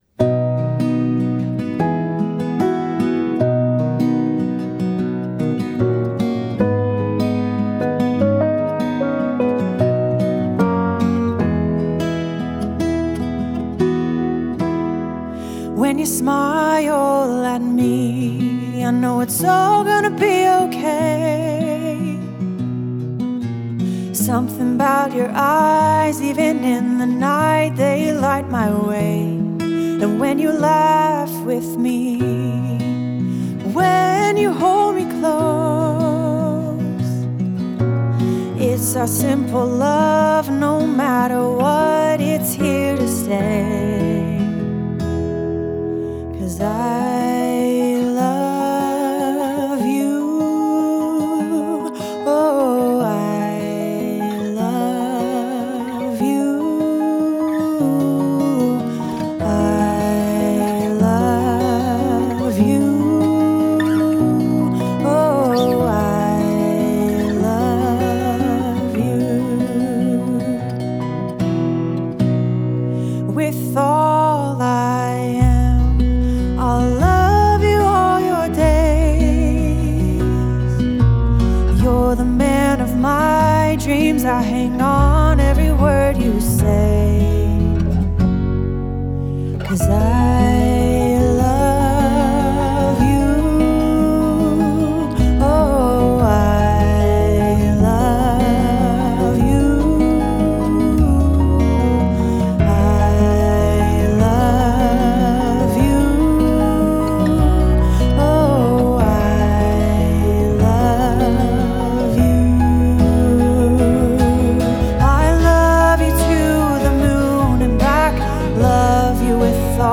Singer-Songwriter: